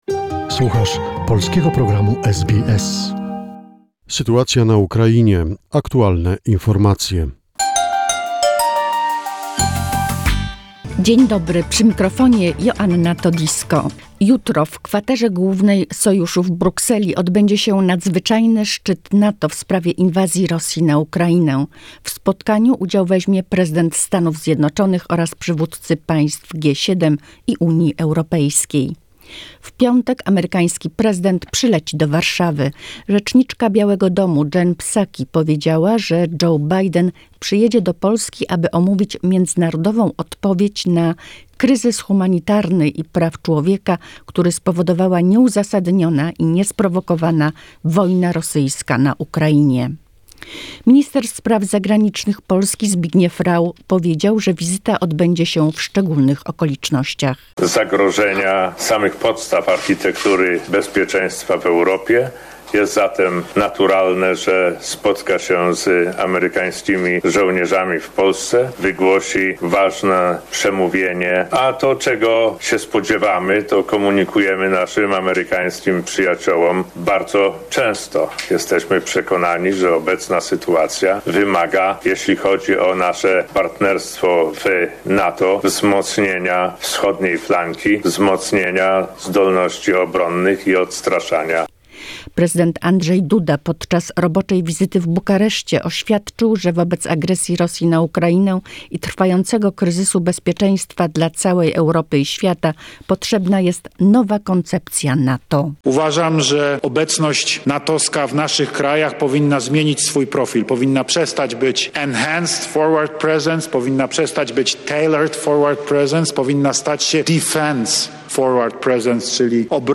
The most recent information about the situation in Ukraine, a short report prepared by SBS Polish.